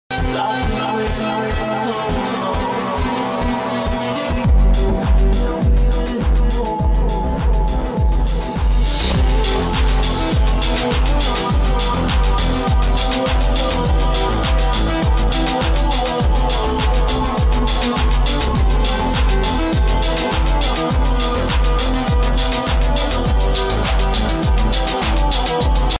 Departing from Tijuana airport at